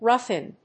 róugh ín